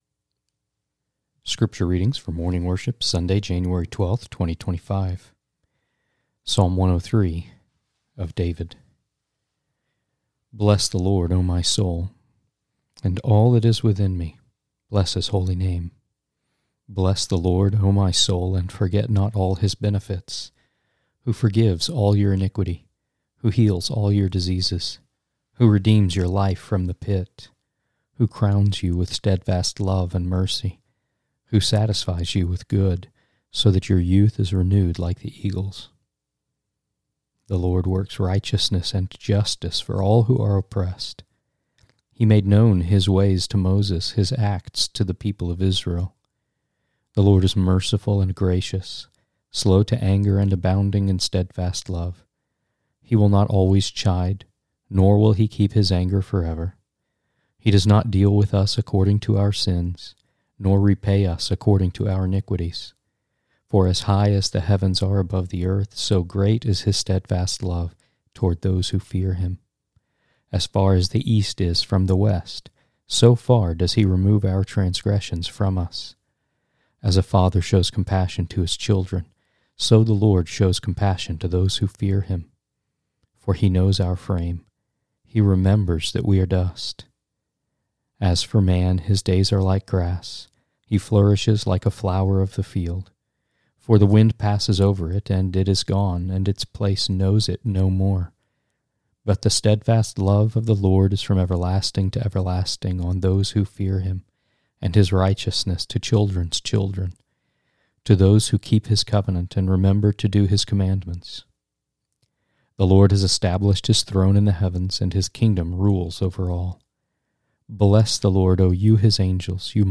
1 Scripture Readings, Morning Worship | Sunday, January 12, 2025